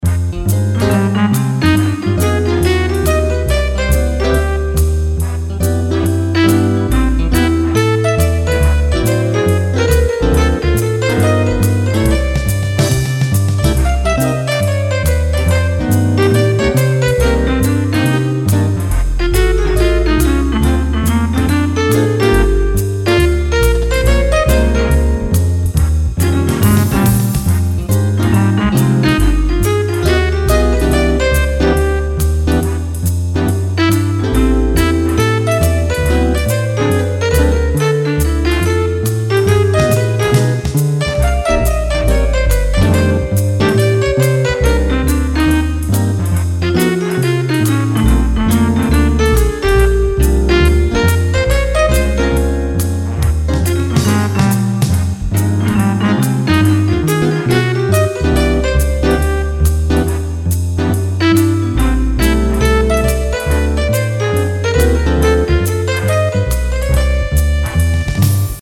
B)賓果背景音樂更新，不同情境引領您進入賓果奇幻世界。